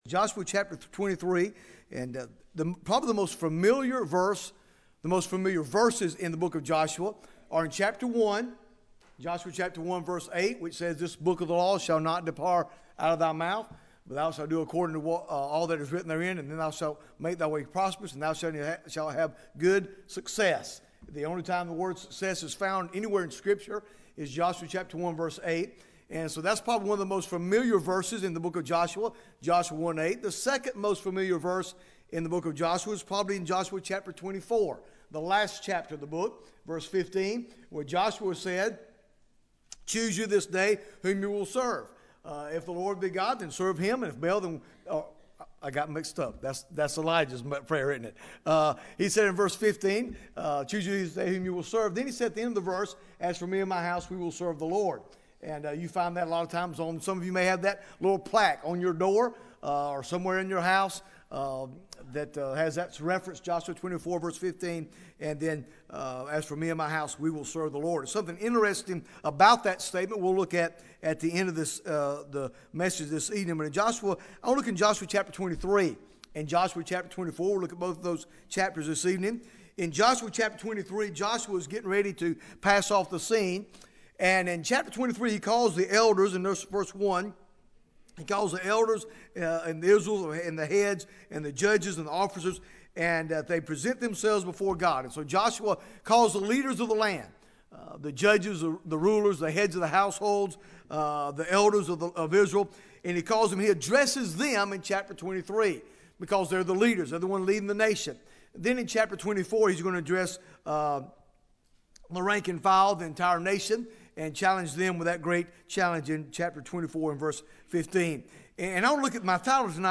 Bible Text: Joshua 23 | Preacher